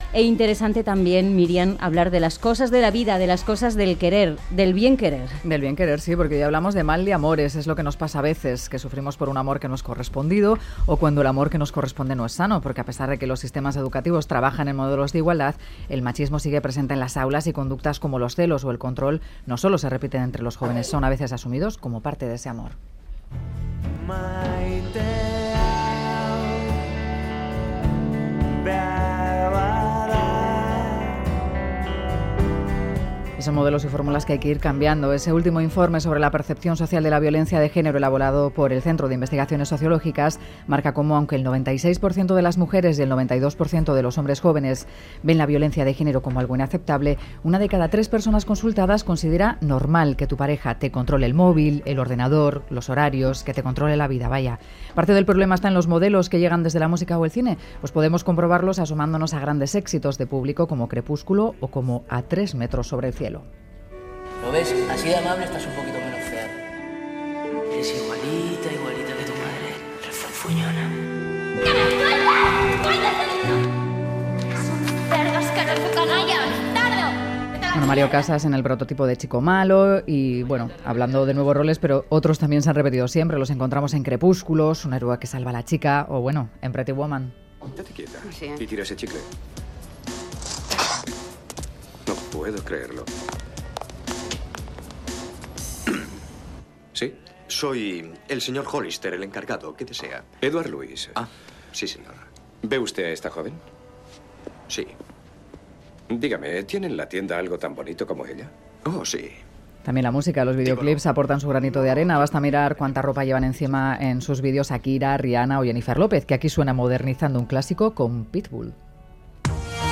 Charlamos de amor y roles con 3 expertas en igualdad y cuestiones de género